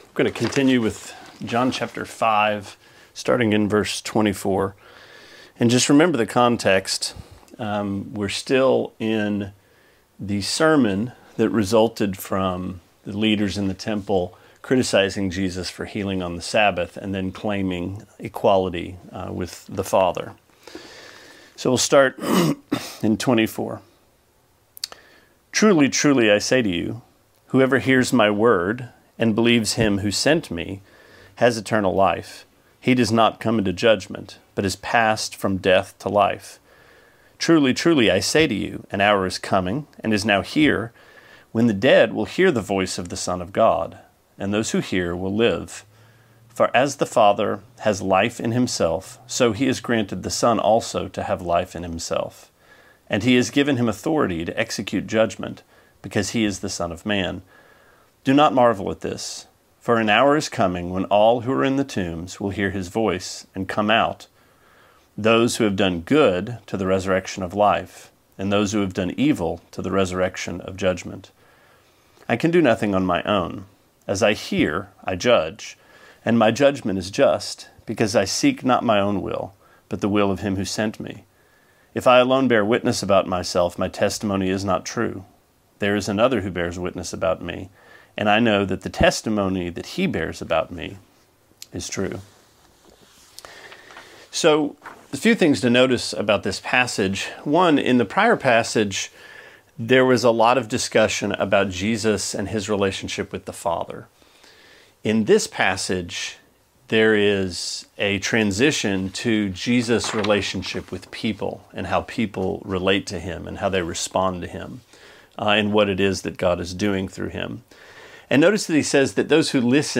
Sermonette 4/29: John 5:24-32: Keep Listening